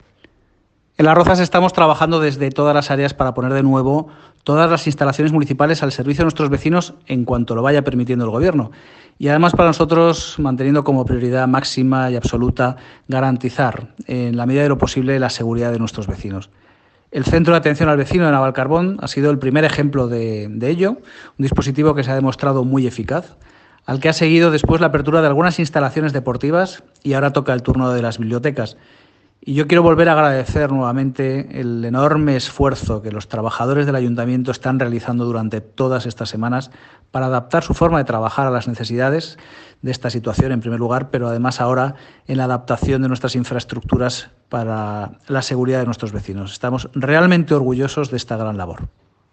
Pincha aquí para escuchar las declaraciones del alcalde de Las Rozas, José de la Uz.